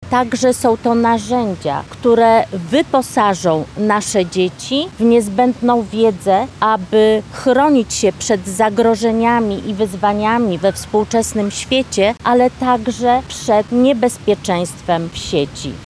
– Edukacja zdrowotna to rzetelna wiedza, którą – jeśli rodzice wyrażą na to zgodę – otrzymają dzieci i nastolatkowie – mówi posłanka Bożena Lisowska.